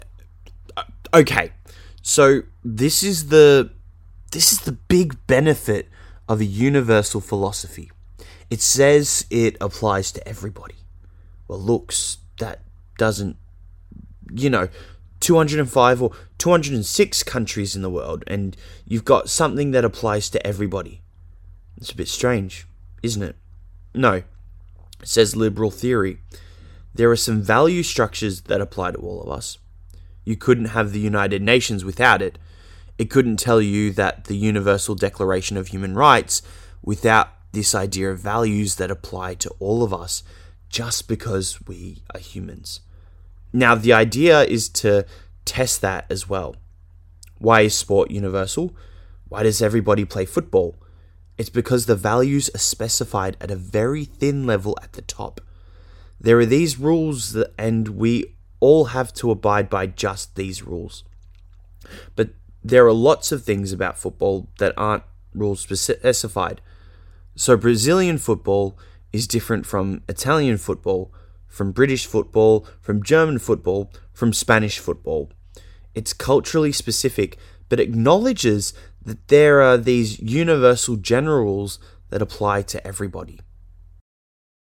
PTE New Questions May – Retell Lecture – Universal Philosophy: